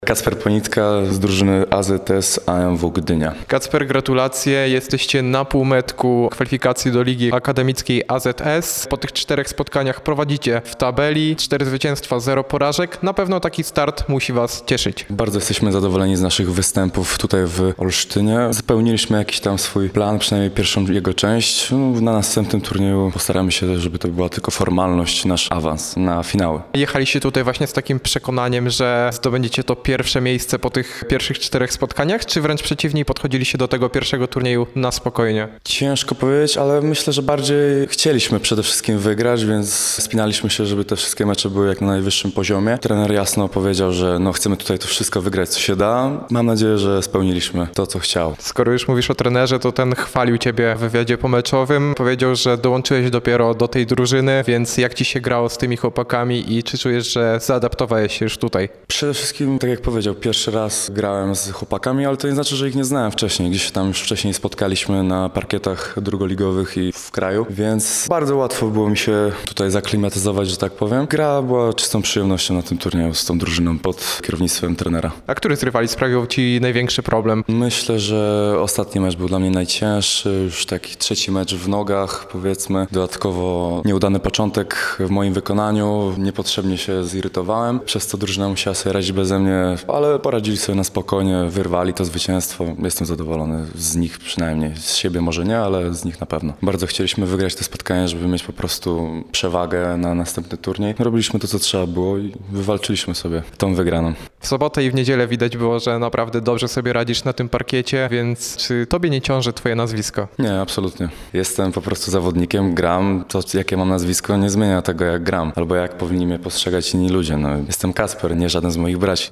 rozmawiał również z